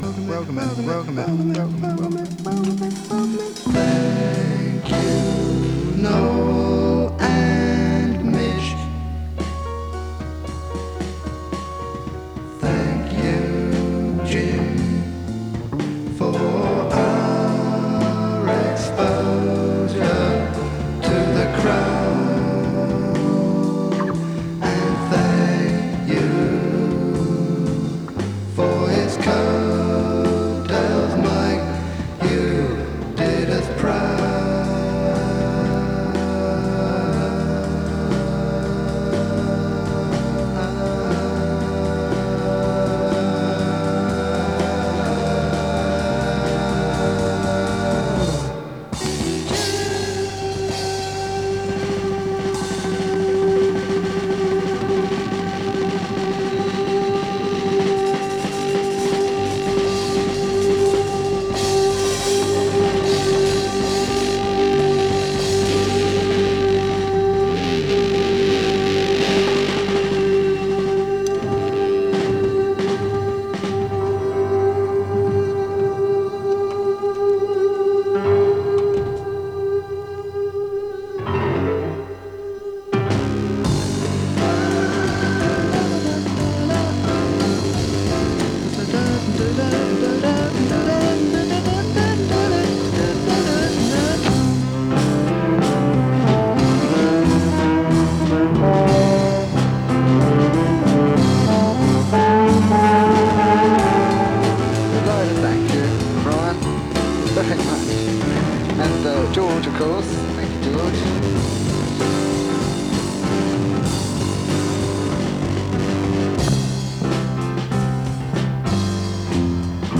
音の薄い部分で軽いサー・ノイズ。時折軽いパチ・ノイズ。